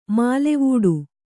♪ mālevūḍu